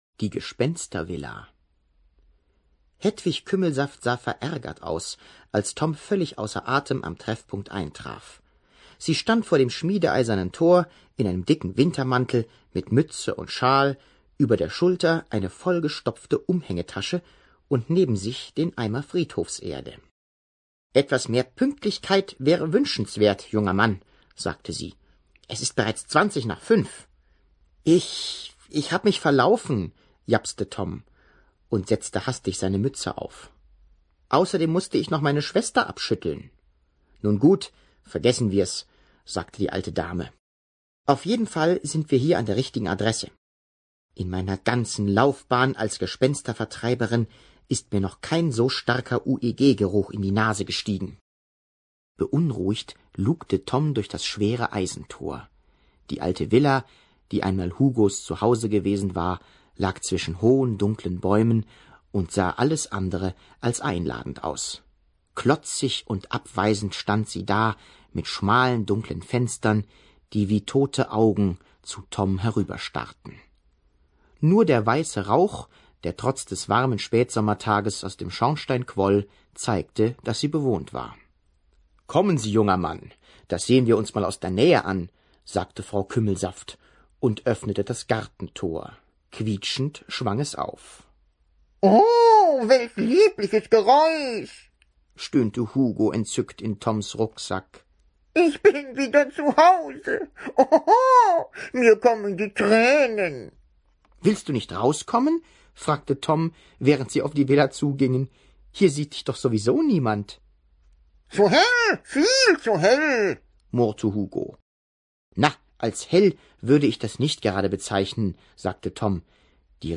Hörbuch: Gespensterjäger.